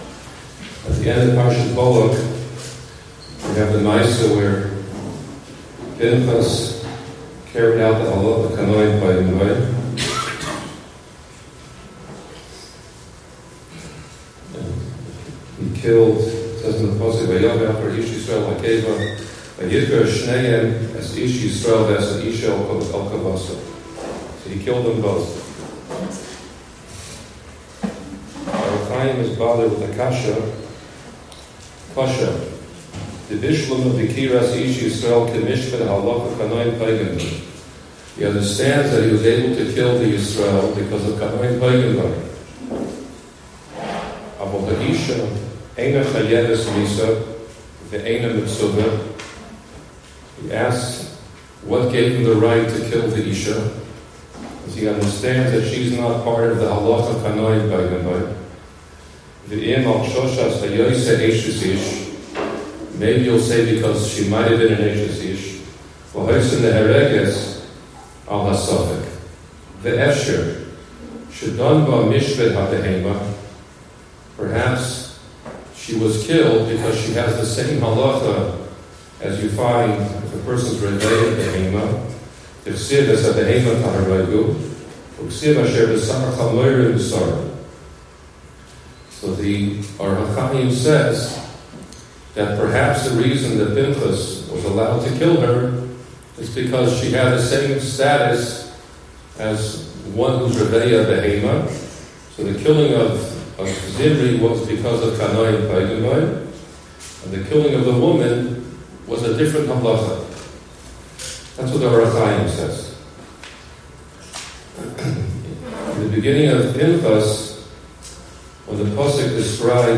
This past Sunday, the Yeshiva held its annual alumni event in the Los Angeles area.